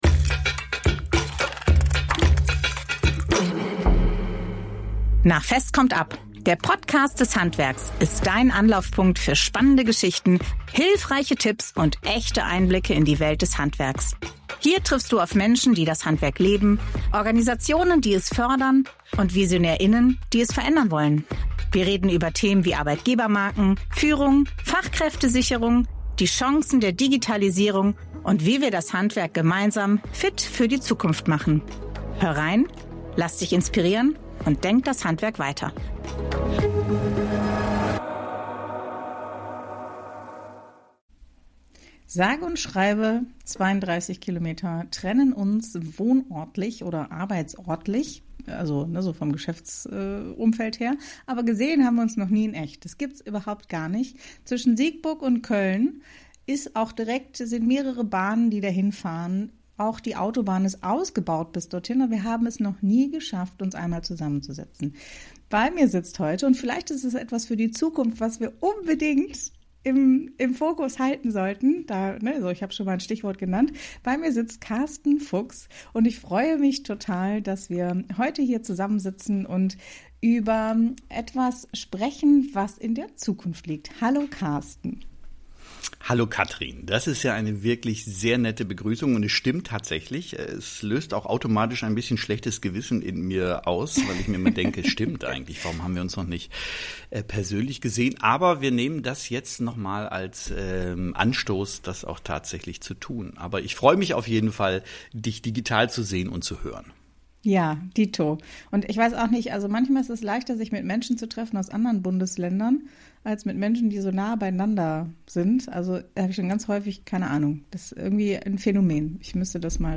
Ein Gespräch voller Energie, Zuversicht und praktischer Impulse für Handwerksbetriebe, Organisationen und alle, die Veränderung gestalten wollen.